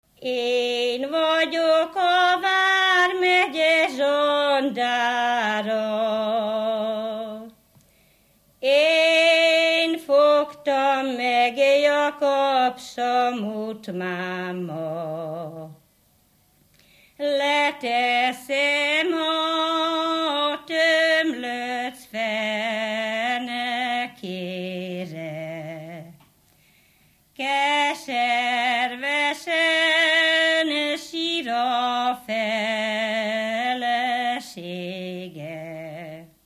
Alföld - Szabolcs vm. - Nagyhalász
Műfaj: Ballada
Stílus: 1.2. Ereszkedő pásztordalok